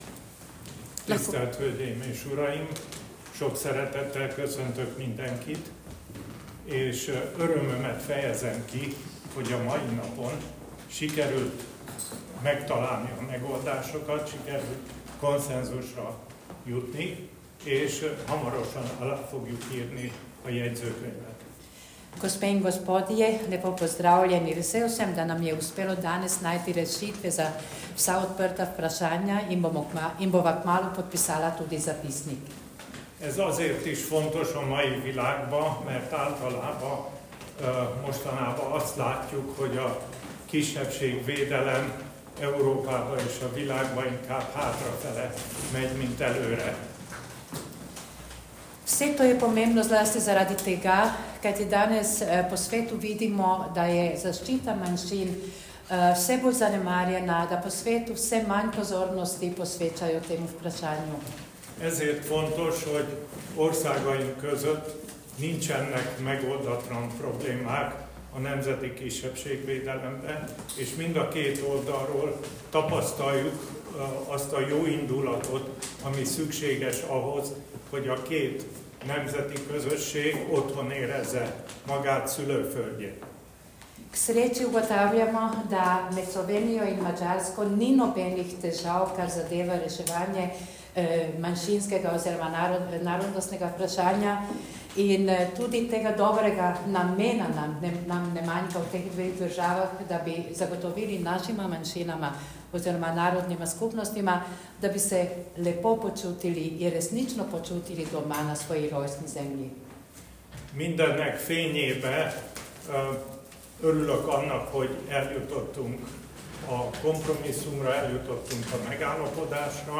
Na povezavah dodajamo zvočna posnetka izjave za medije obeh sopredsedujočih ob zaključku zasedanja:
Izjava Ferenc Kalmar